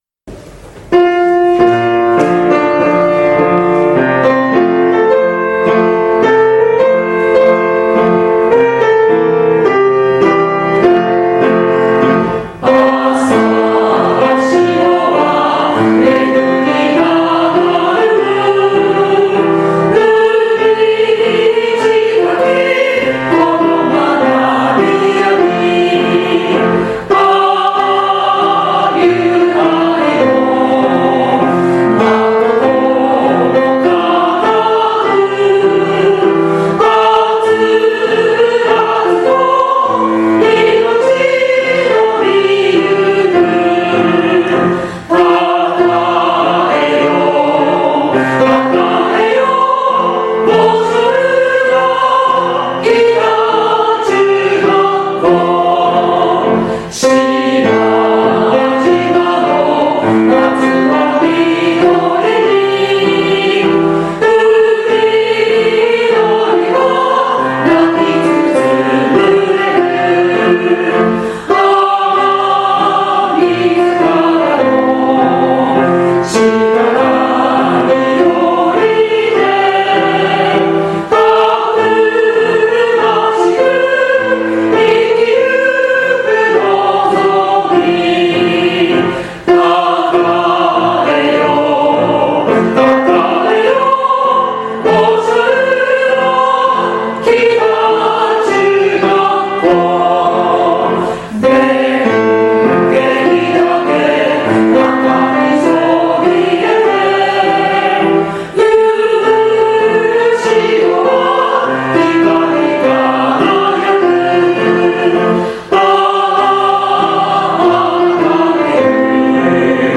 現行政区 旧行政区 No 中学校名 校歌楽譜・歌詞・概要 校歌音源（歌・伴奏）  備考
goshourakitachu_kouka_gattsho.mp3